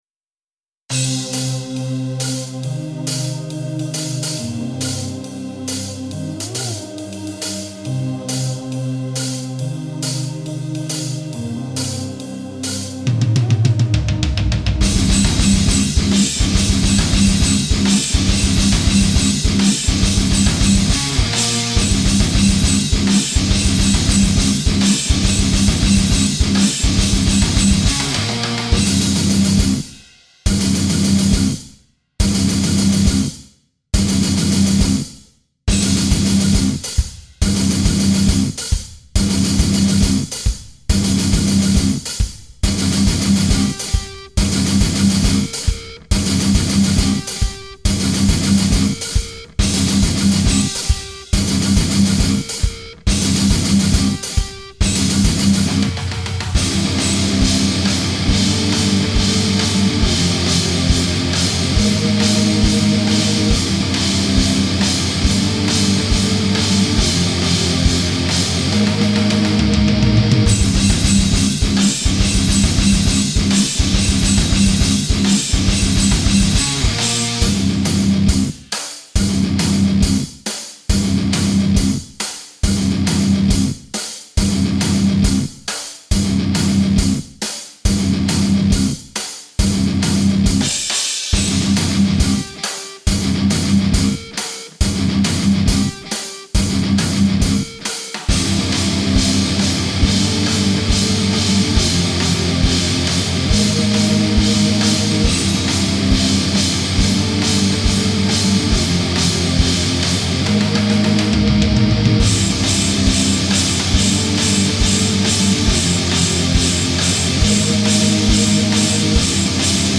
Folgendes, ich bin kein Profi was Aufnahmen angeht; Song erstellt mit zwei E-Gitarren, jeder hat seinen Röhrentop (Engl und Peavey).
also klingt schon besser, aber ich denke du hast versucht mit EQ und Kompression das zu Patchworken